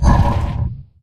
anomaly_gravy_blast01.ogg